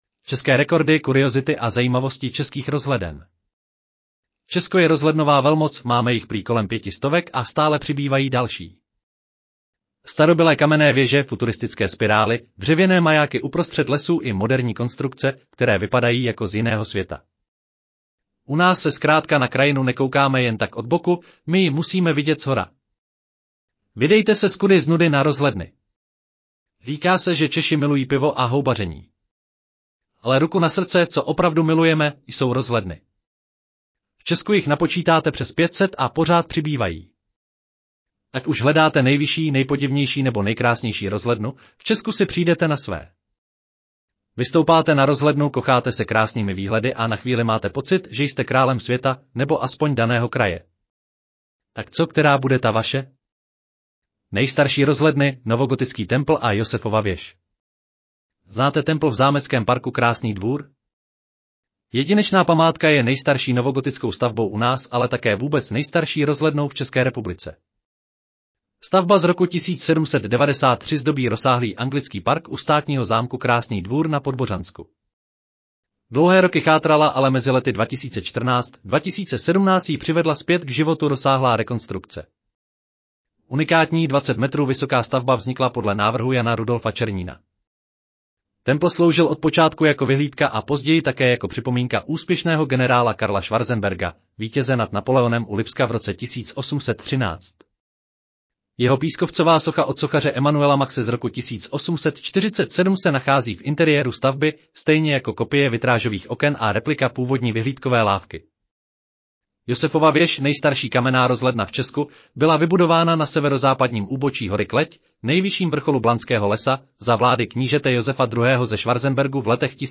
Audio verze článku České rekordy: kuriozity a zajímavosti českých rozhleden